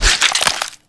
break2.ogg